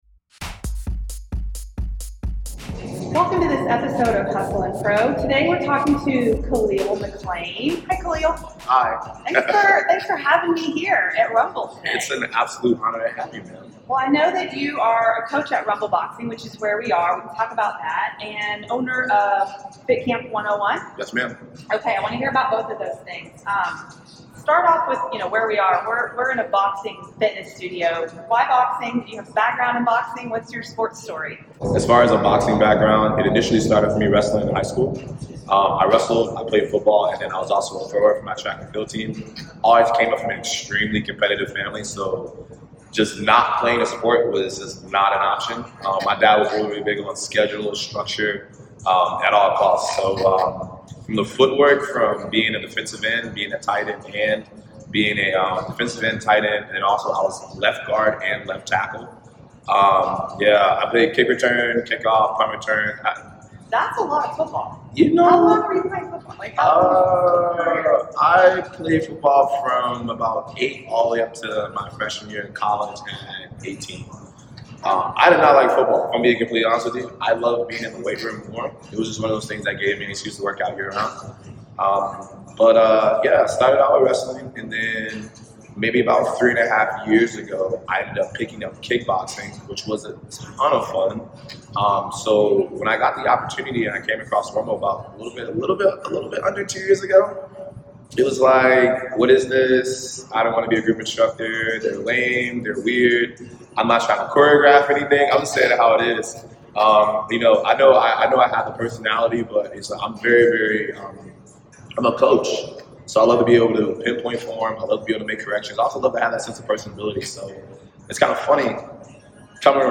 Thanks for Rumble Boxing for giving us the studio for a few minutes (pardon the echo!).